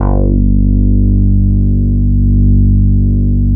90 BASS   -R.wav